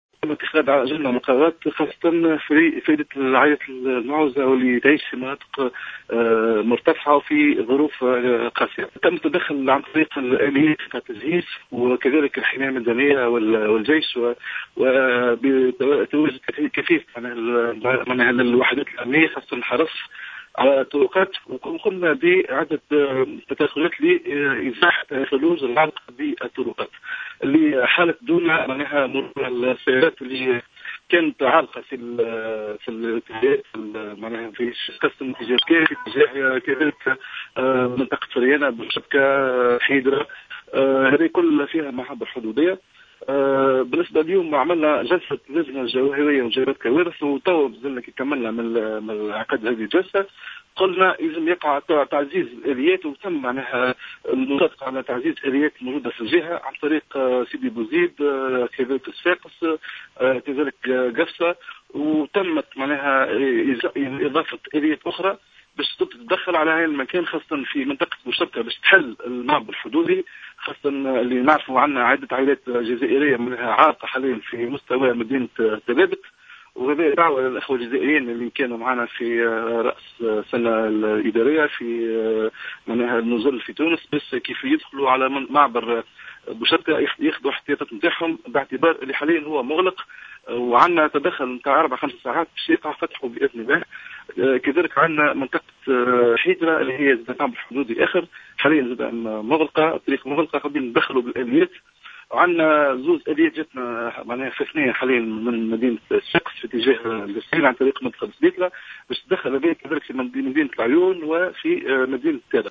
وقال والي القصرين ورئيس اللجنة الجهوية لمجابهة الكوارث الطبيعية عاطف بوغطاس في تصريح ل "جوهرة أف أم" إنه تم التدخل عبر الجرّافات في عدد من المناطق لفتح الطرقات والمسالك وفك عزلتها،مشيرا إلى أنّ المعابر الحدودية مغلقة حاليا بسبب الثلوج.